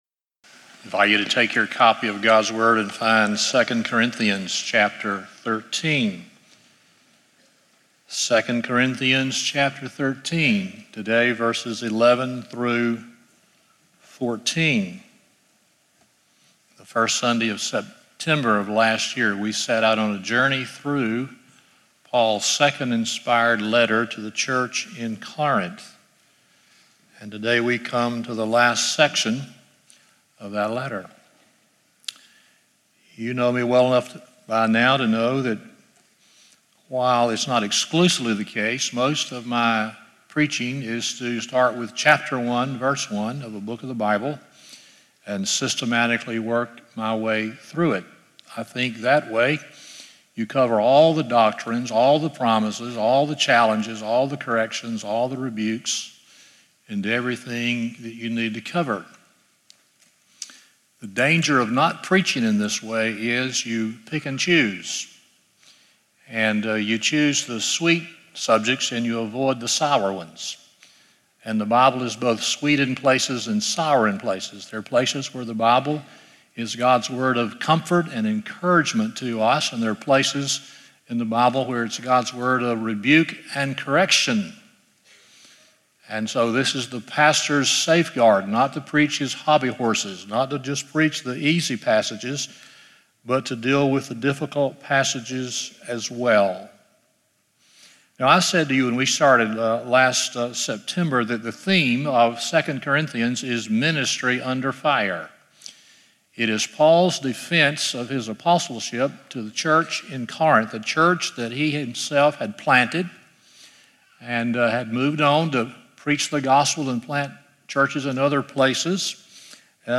2 Corinthians 13:11-14 Service Type: Sunday Morning 1.